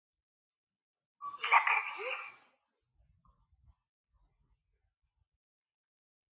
AUDIOCUENTO BASADO EN LA CANCION DE JUDITH AKOSCHKY